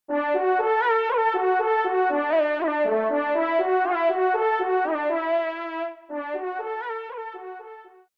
FANFARE
Localisation :  Nord Pas-de-Calais (Flandre, Artois et Hainaut)
Pupitre de Chant